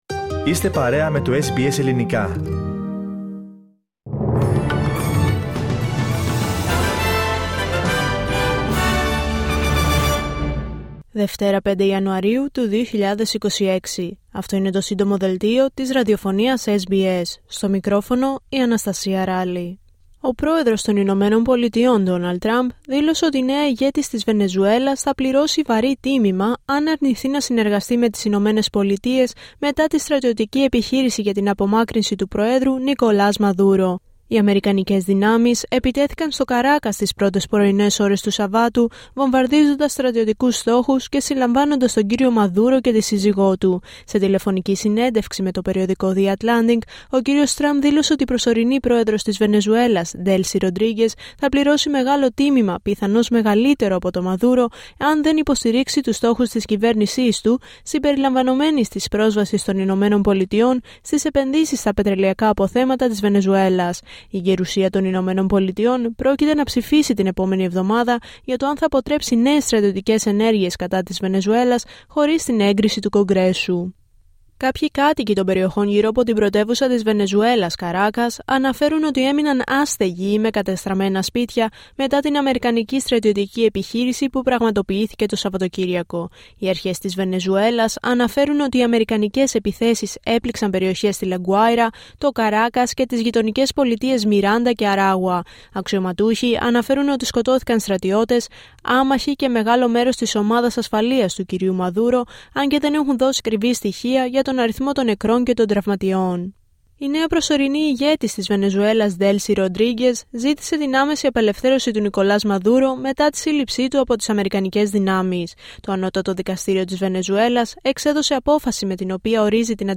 H επικαιρότητα έως αυτή την ώρα στην Αυστραλία, την Ελλάδα, την Κύπρο και τον κόσμο στο Σύντομο Δελτίο Ειδήσεων της Δευτέρας 5 Ιανουαρίου 2026.